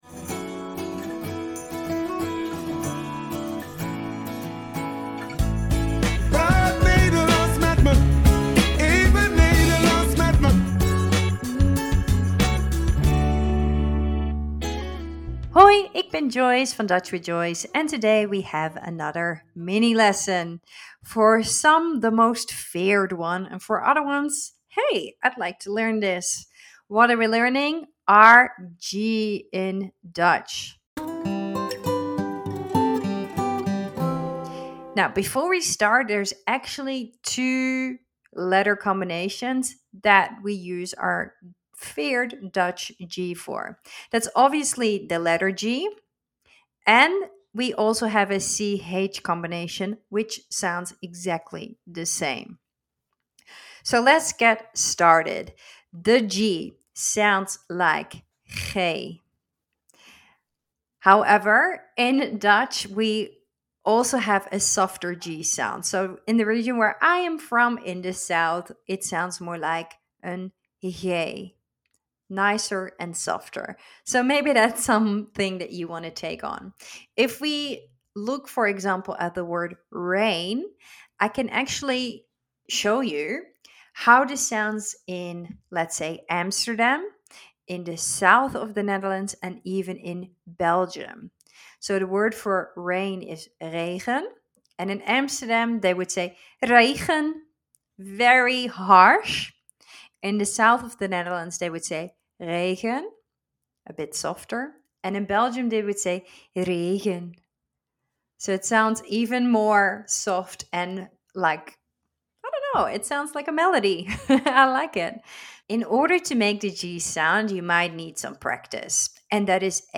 Words from lesson 14: G-sound In Dutch there are two letter combinations that make the G-sound: G and CH.